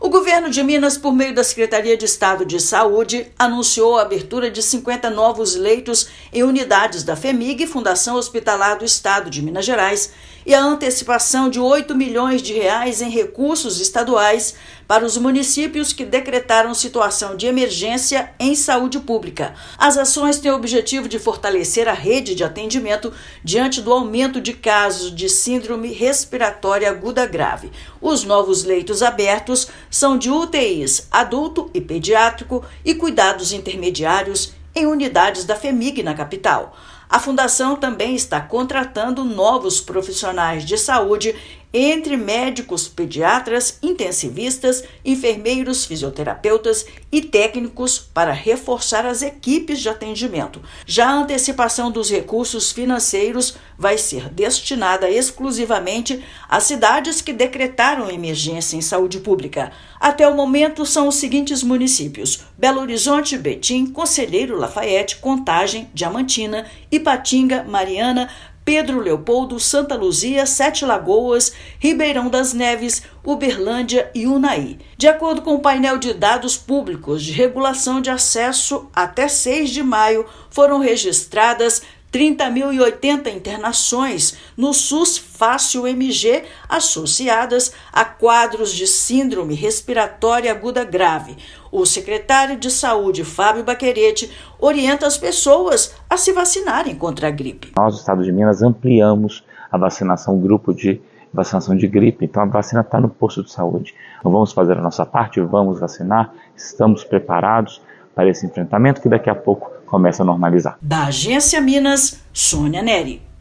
Medidas incluem abertura de 50 leitos em hospitais da Fhemig e antecipação de R$ 8 milhões para municípios em situação de emergência, visando reduzir a pressão assistencial durante o pico sazonal. Ouça matéria de rádio.